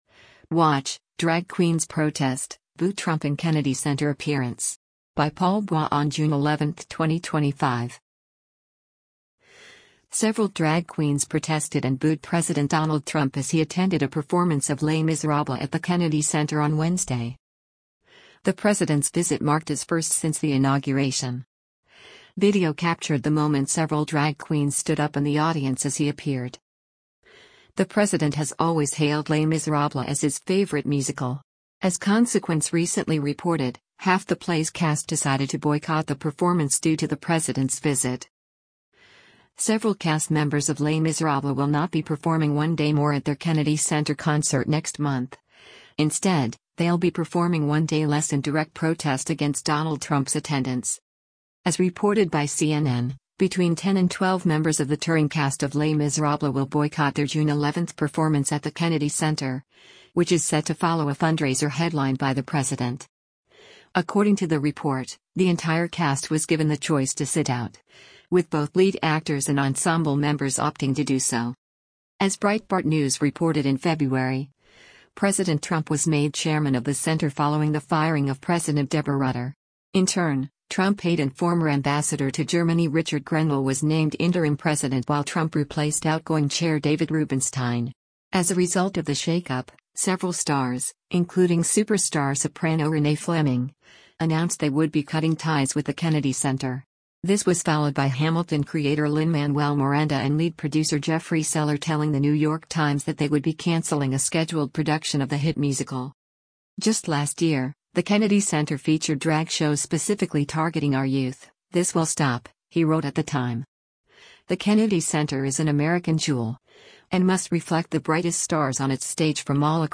Several drag queens protested and booed President Donald Trump as he attended a performance of Les Misérables at the Kennedy Center on Wednesday.
Video captured the moment several drag queens stood up in the audience as he appeared: